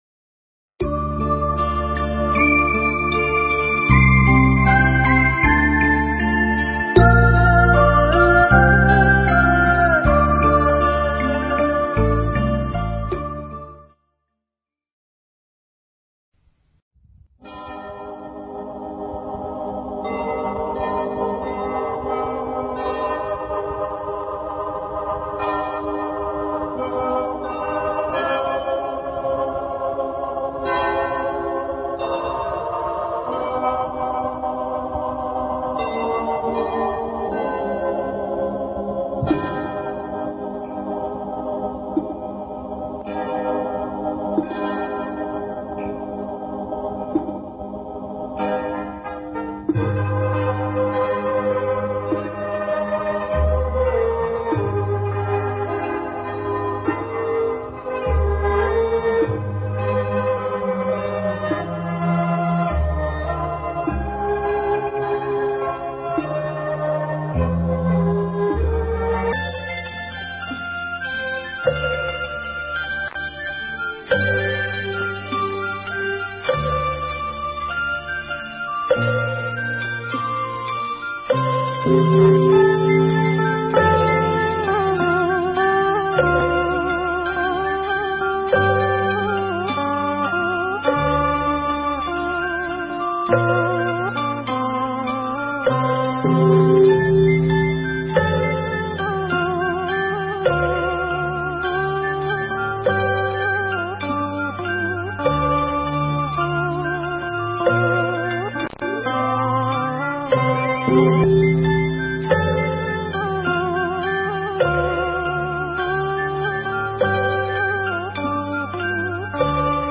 Nghe Mp3 thuyết pháp Lời Nguyện Đầu Năm
Ngh3 mp3 pháp thoại Lời Nguyện Đầu Năm